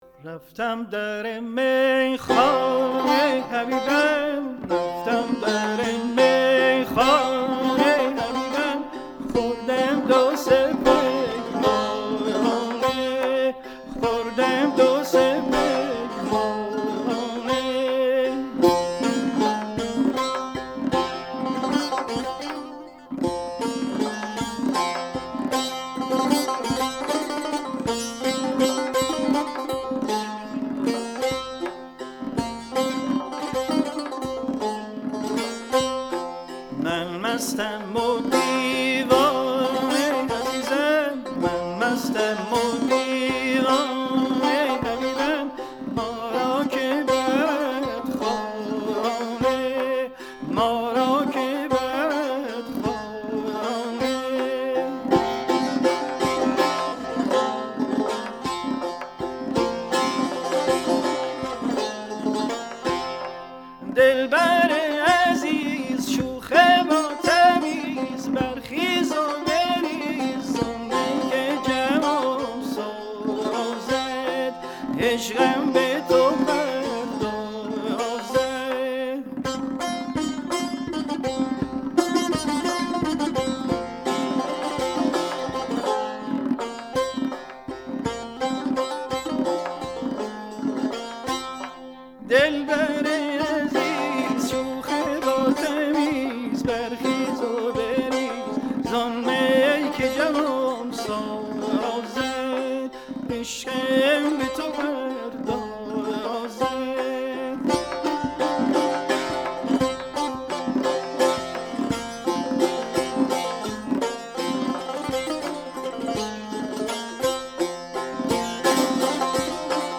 سنتی